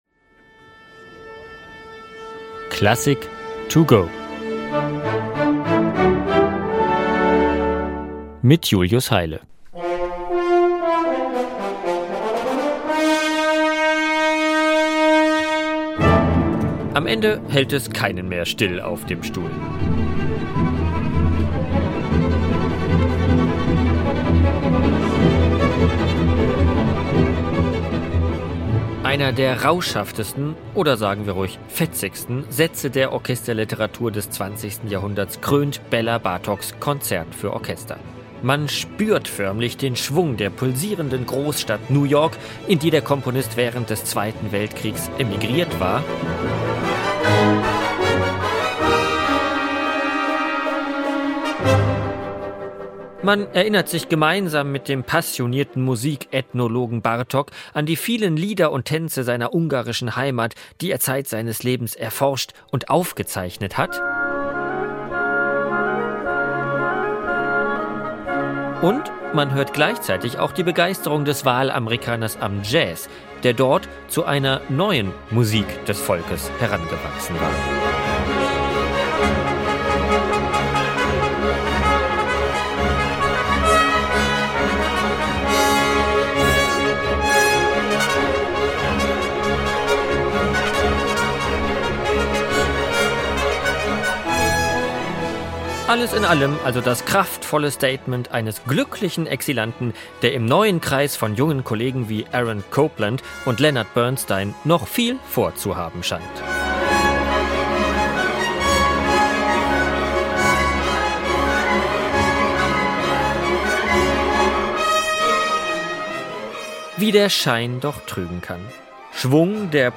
"Klassik to Go", der kurzen Werkeinführung für unterwegs.